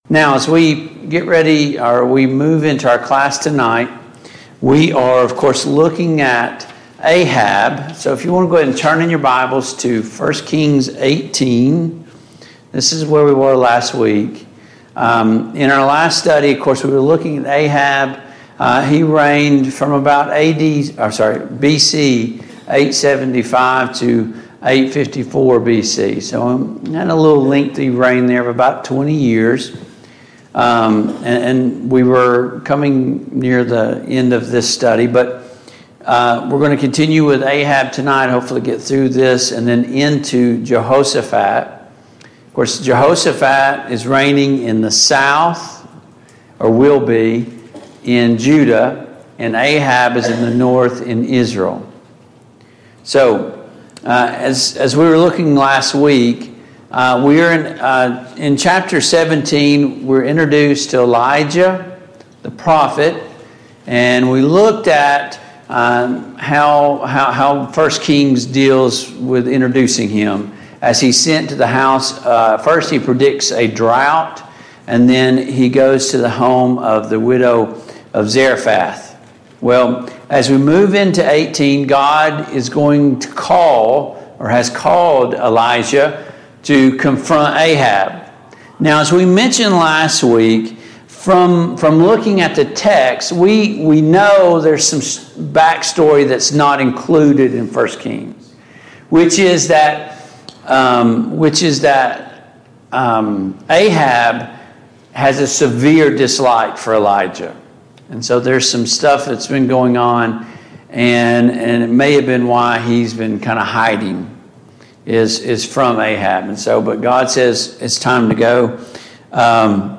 Passage: 1 Kings 18, 1 Kings 19 Service Type: Mid-Week Bible Study